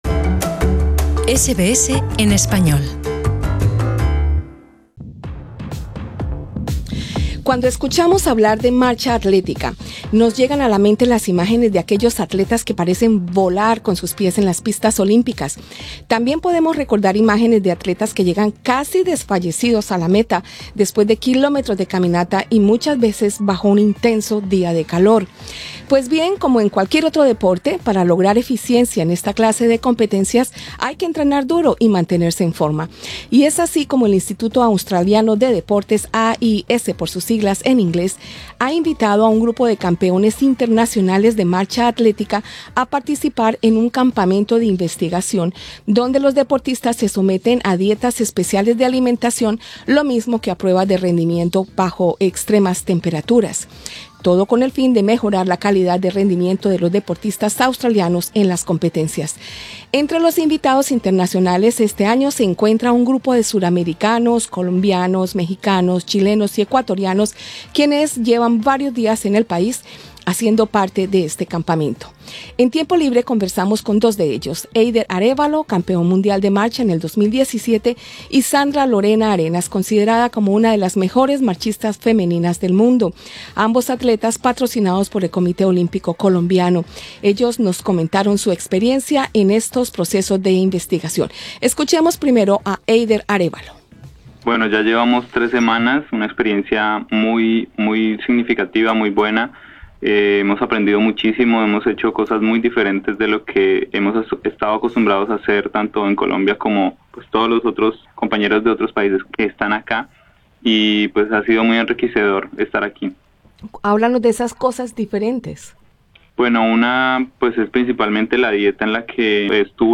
Ambos nos comentan su experiencia en estos procesos de investigación. Escucha las entrevistas arriba en nuestro podcast.